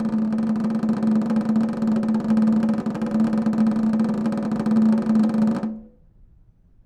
Snare2-rollNS_v1_rr1_Sum.wav